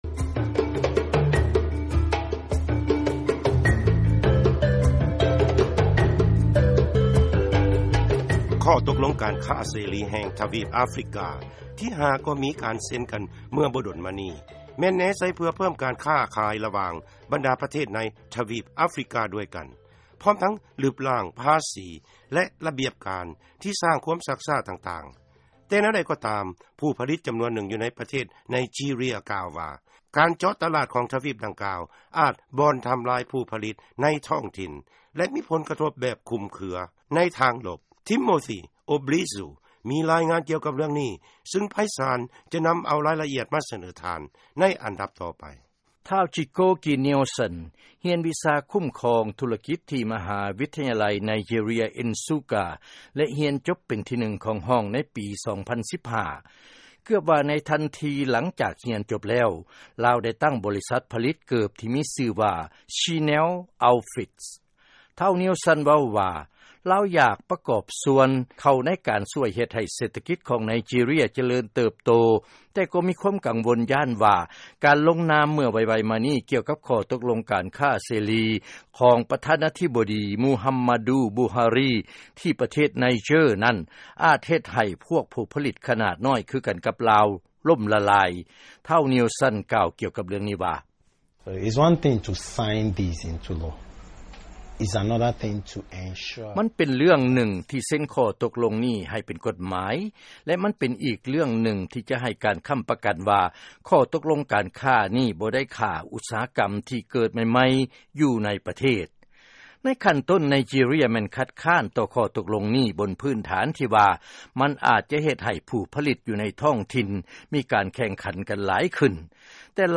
ເຊີນຟັງລາຍງານ ເປັນຫຍັງ ຜູ້ຜະລິດໄນຈີເຣຍ ຈຶ່ງເປັນຫ່ວງ ກ່ຽວກັບ ຂໍ້ຕົກລົງການຄ້າເສລີ